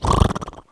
HORSE 2.WAV